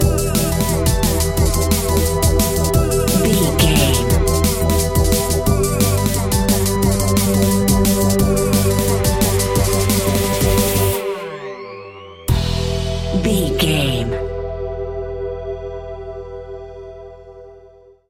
Aeolian/Minor
Fast
aggressive
powerful
futuristic
hypnotic
industrial
dreamy
drum machine
synthesiser
electronic
sub bass
synth leads